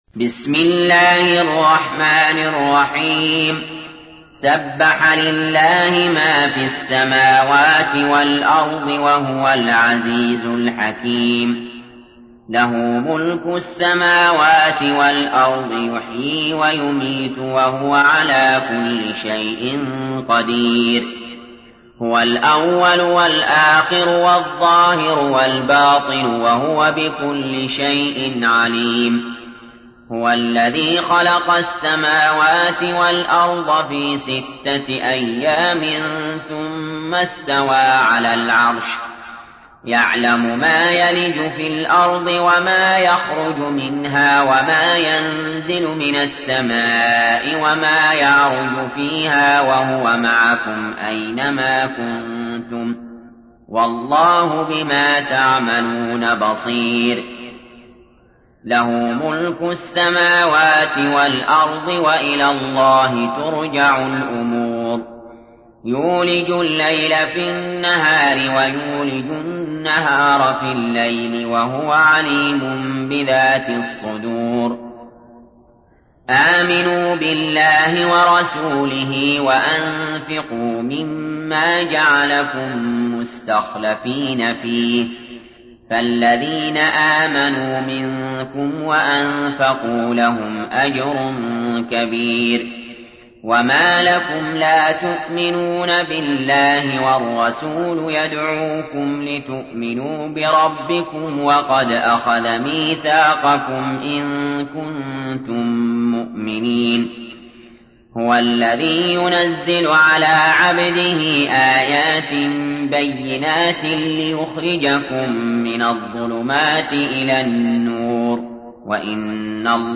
تحميل : 57. سورة الحديد / القارئ علي جابر / القرآن الكريم / موقع يا حسين